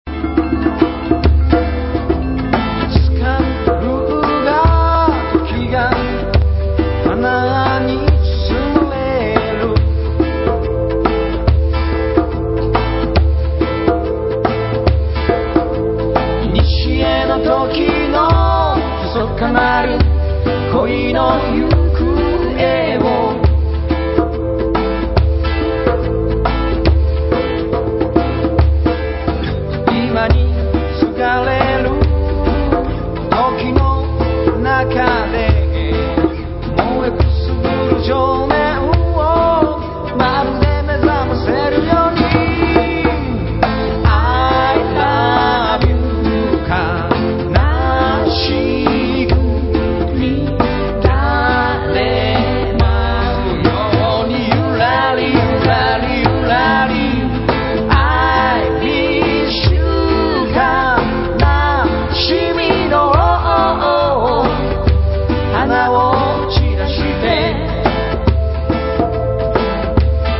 レゲエに大和言葉をのせてみたい